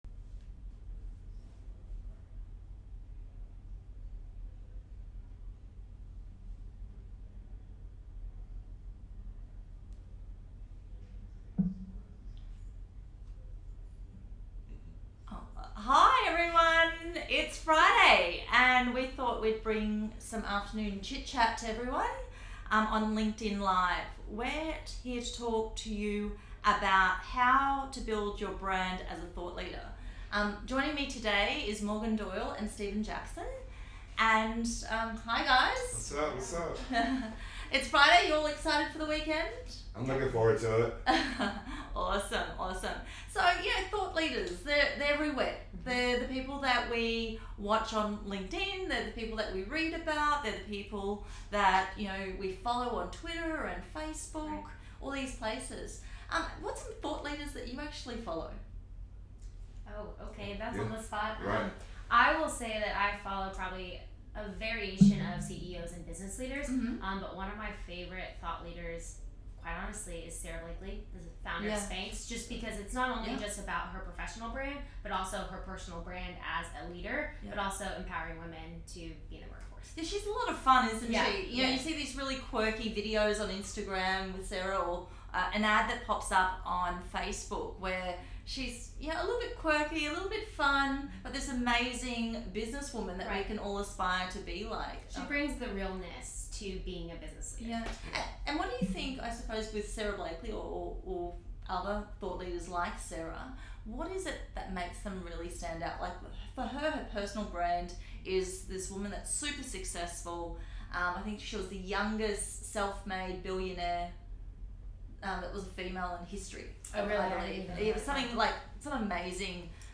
Join this week’s conversation on thought leadership